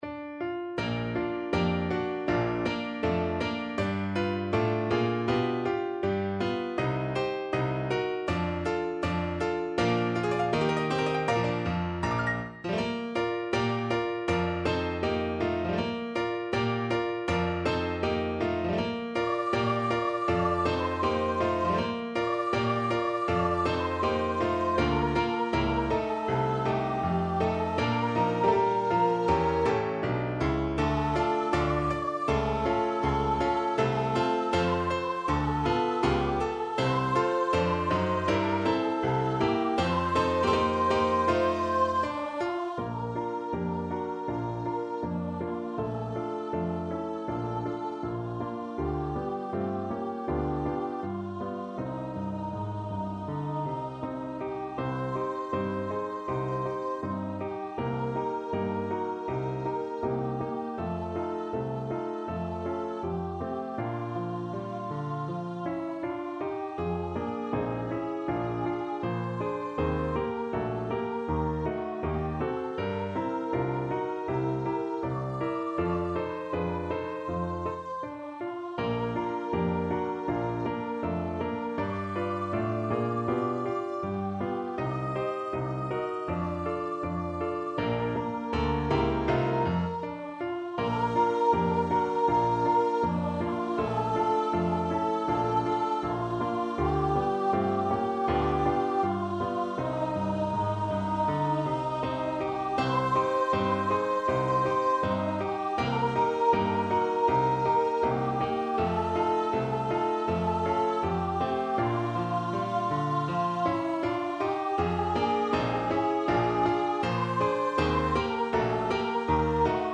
D5-Eb6
2/2 (View more 2/2 Music)
Moderato =80
Pop (View more Pop Voice Music)